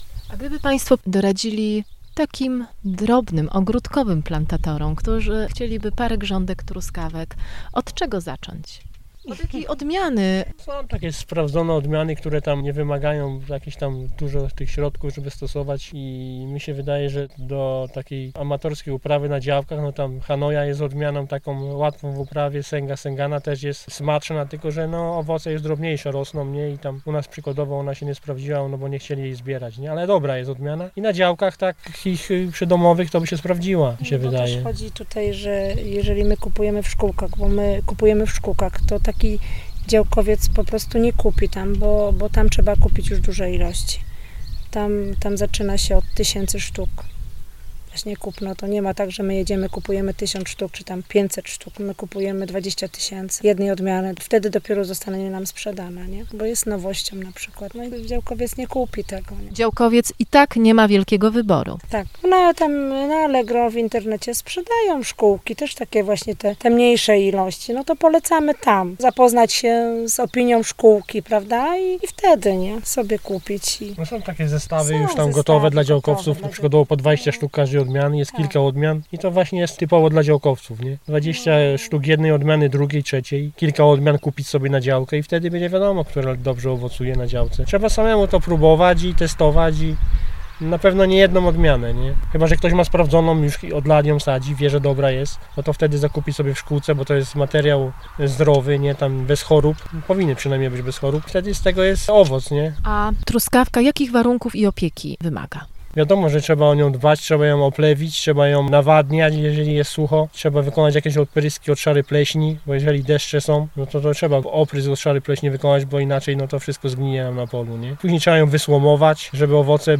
Audycję rozpoczynamy rozmową wykraczającą poza ogród, ale dotyczącą przyrody i jednego z jej pomników na terenie województwa Lubuskiego, drzewa Wiedźmin rosnącego w Komorowie. Najstarszy wiąz w Polsce został zniszczony przez wichurę.